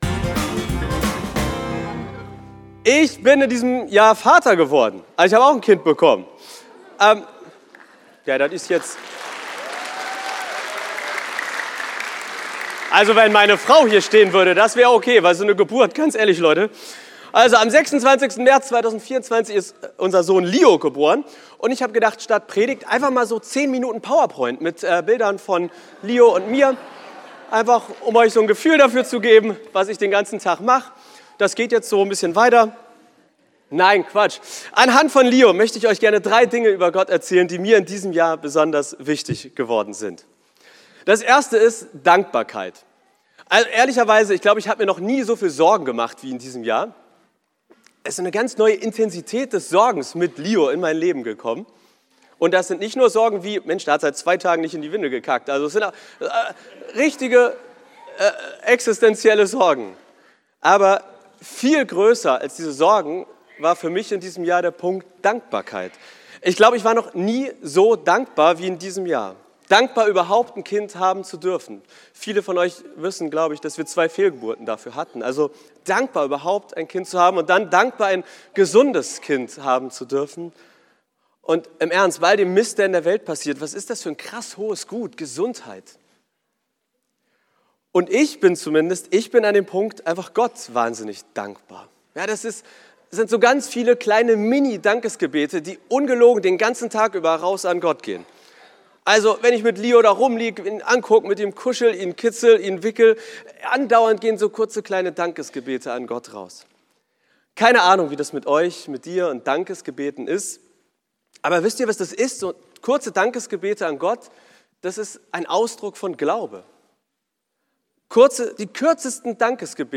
weihnachtspredigt-2024.mp3